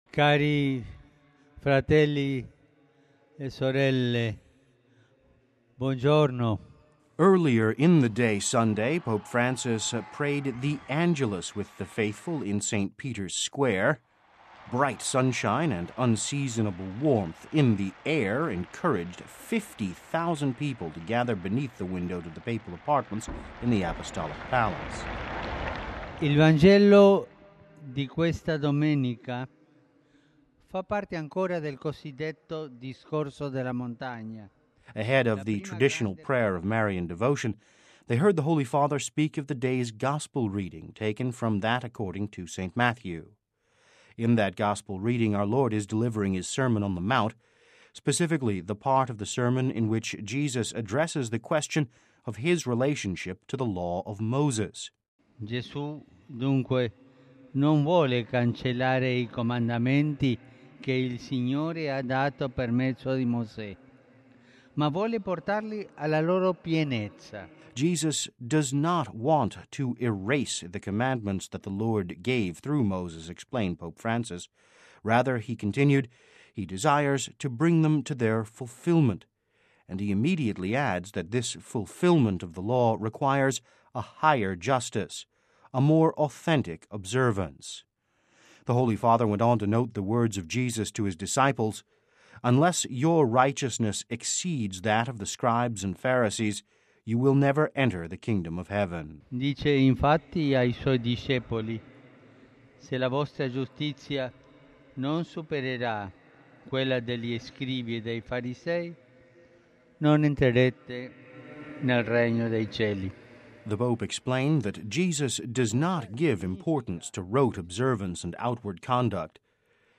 (Vatican Radio) Pope Francis prayed the Angelus with the faithful in St Peter’s Square this Sunday.